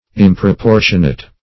\Im`pro*por"tion*ate\